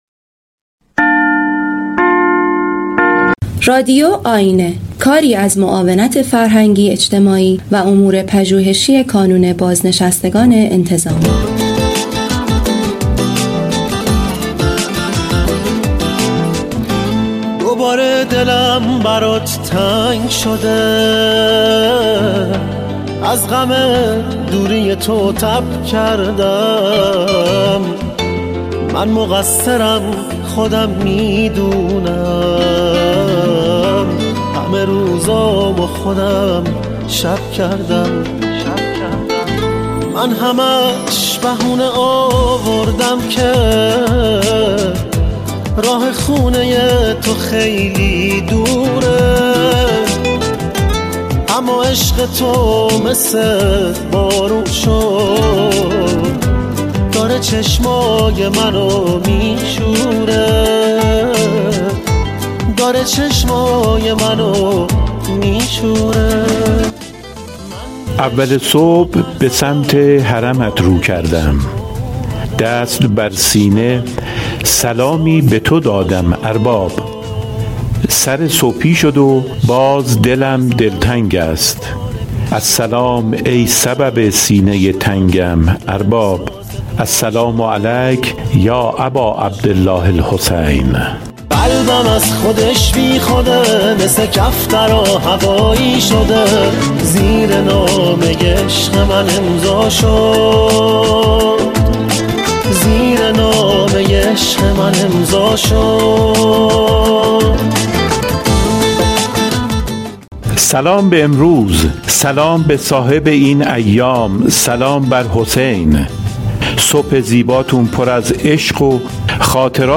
متخصص طب سنتی در خصوص بهترین زمان خواب مناسب صحبت می کند